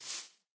grass4.ogg